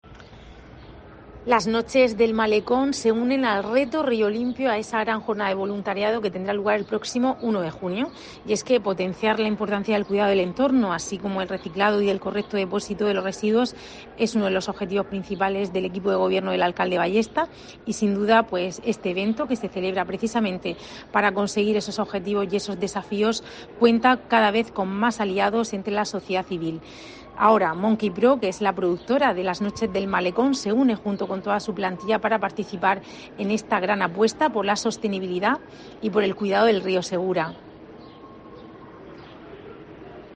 Rebeca Pérez, vicealcaldesa de Murcia